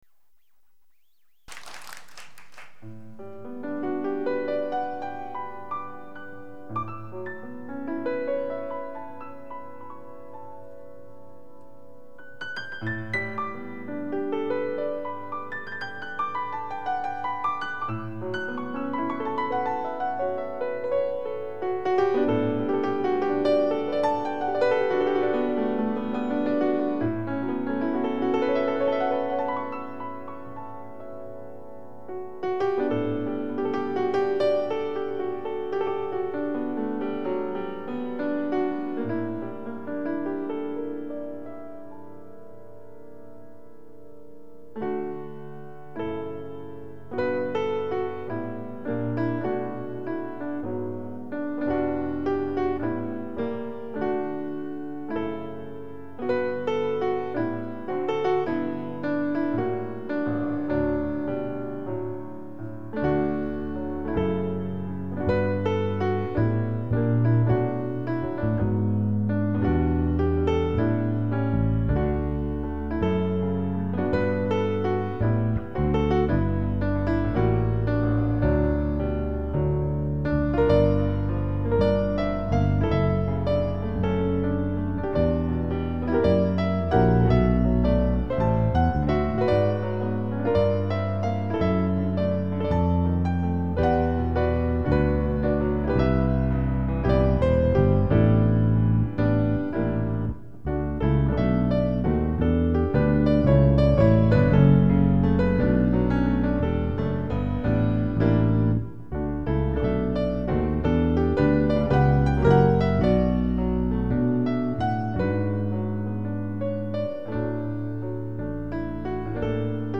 2006-02-08 오전 10:47:00 ebs추출버전이고요...베이스랑 연주하니 너무 좋군요..
음질 괜찮습니당~